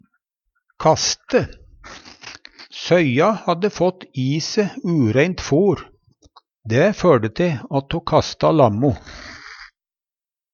kaste - Numedalsmål (en-US)